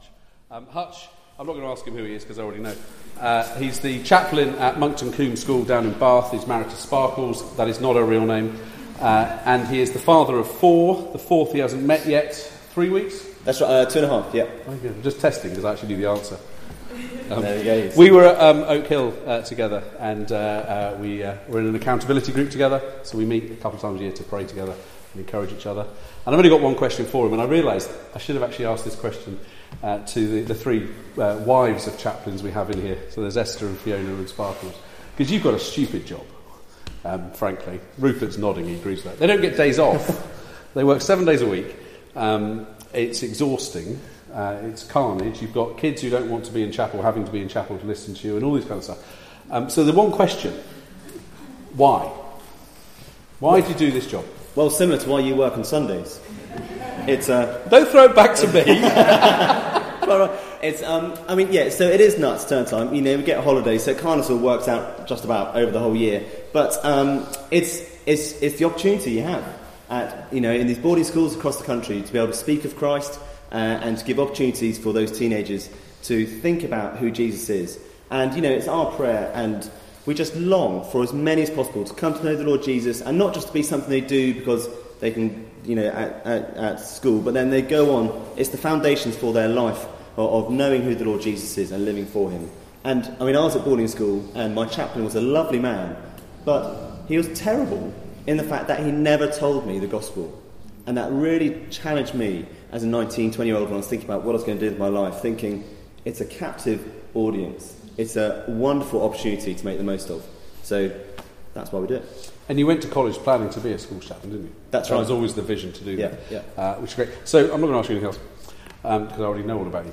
Passage: Hebrew 3:1-6 Service Type: Weekly Service at 4pm